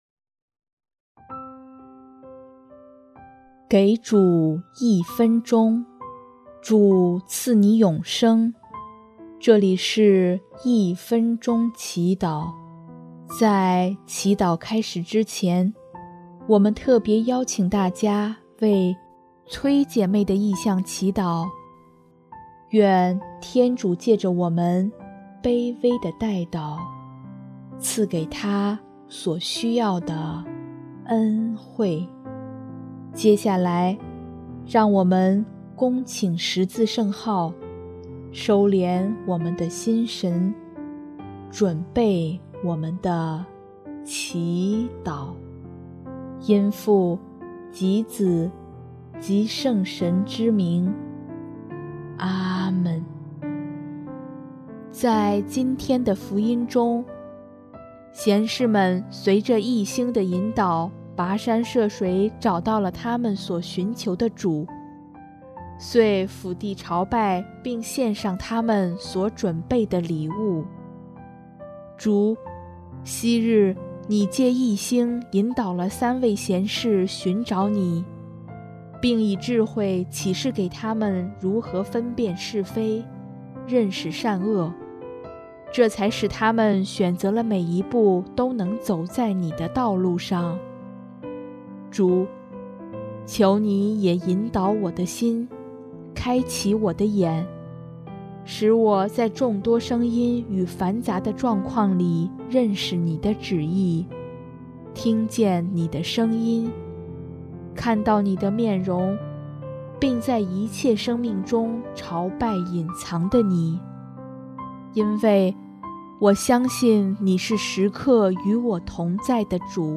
音乐： 主日赞歌《藉祂而得救》